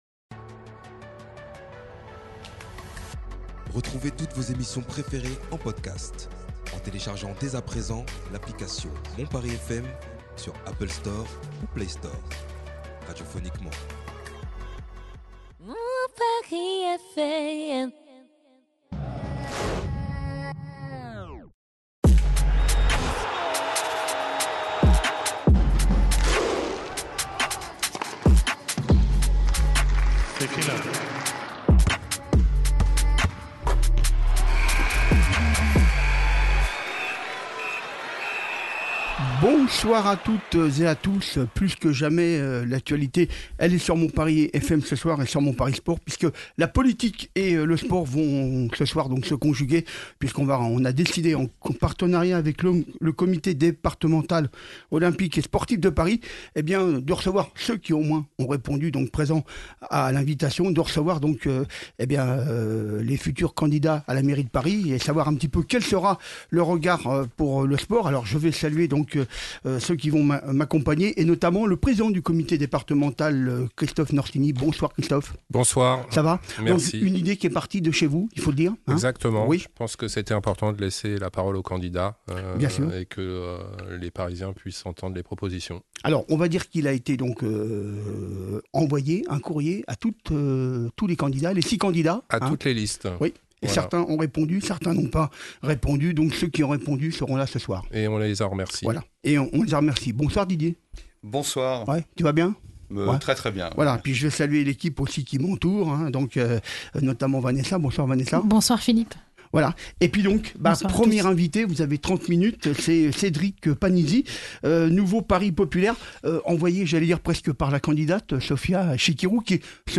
En partenariat avec le CDOS 75, nous recevrons pour évoquer l’avenir du sport sous la prochaine mandature,les principaux candidats (ou leurs porte-paroles) à la mairie de Paris ayant accepté notre invitation. Ainsi se succéderont à notre micro, les listes du Nouveau Paris Populaire et Paris est à vous !